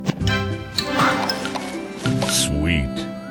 Download Free Candy Crush Sound Effects